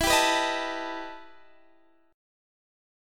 Listen to EmM11 strummed